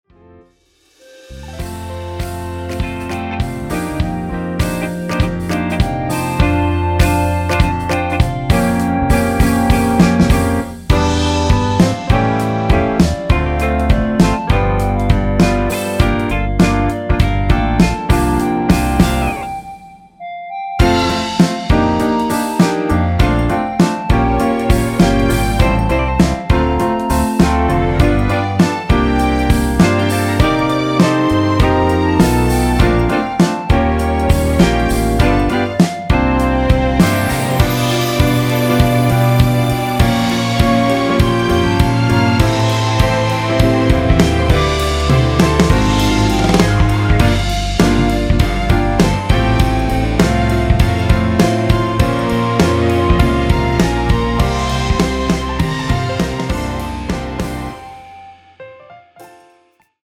원키에서(+2)올린 (1절+후렴)으로 진행되는 멜로디 포함된 MR입니다.
앞부분30초, 뒷부분30초씩 편집해서 올려 드리고 있습니다.
중간에 음이 끈어지고 다시 나오는 이유는